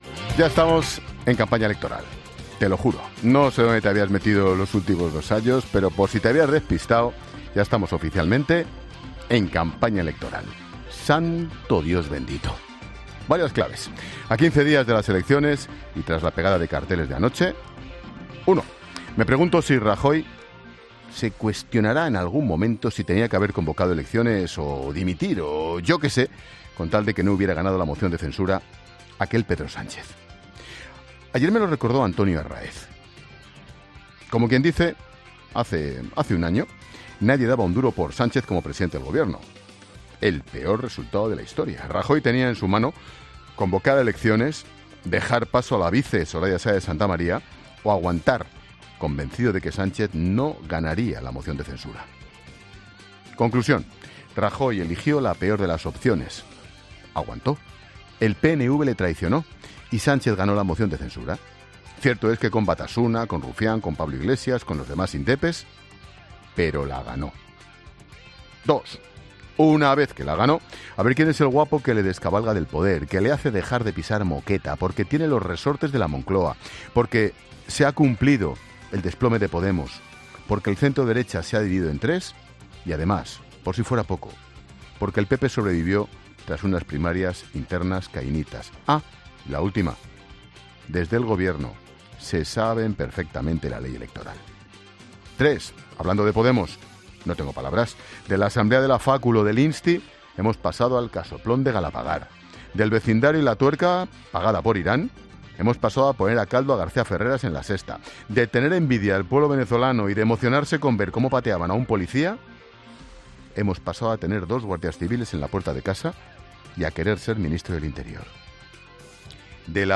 Monólogo de Expósito
Ángel Expósito analiza la actualidad en 'La Linterna'